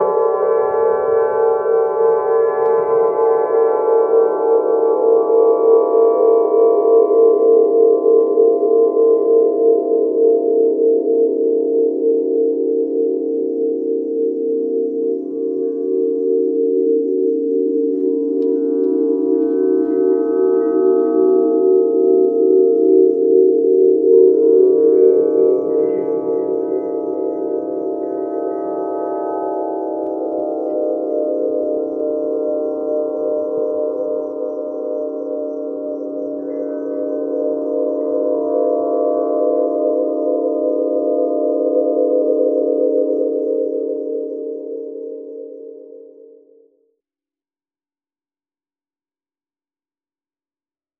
Listen to a sample recording of the Seed of Life wind harp.
(All Wind Harps have their own unique sounds depending on seasonal wind currents and place of installation).
• Strings : 9 tunable strings with stainless steel tuning pins